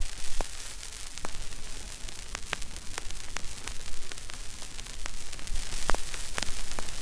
[fx] vinylz.wav